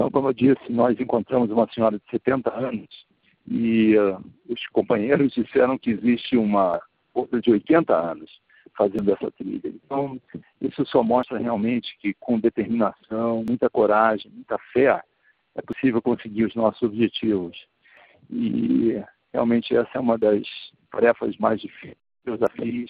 (Lost transmission) Today we met a lady of 70 years old and her friends said that there is a lady of 80 years climbing the mountain. It just really shows that with determination, courage and great faith, it is possible to achieve our objectives and this really is one of the hardest tasks I've ever done.